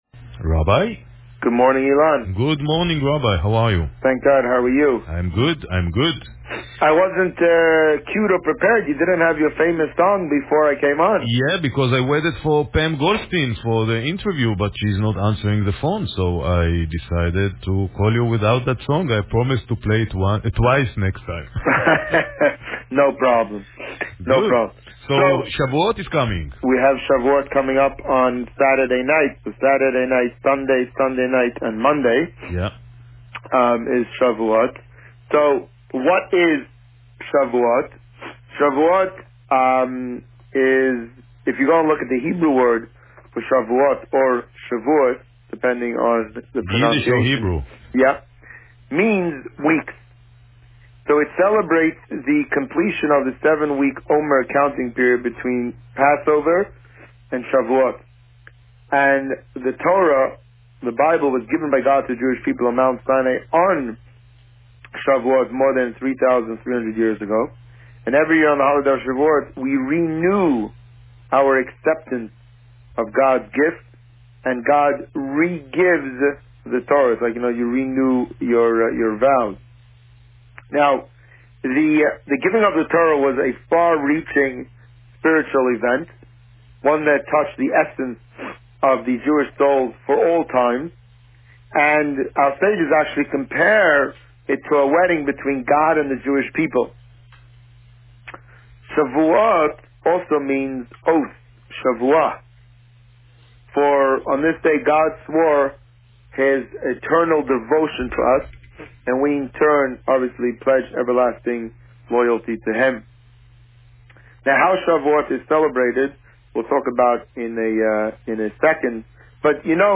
This week, the rabbi spoke about the upcoming Shavuot holiday and the meaning of Shavuot to us. Listen to the interview here.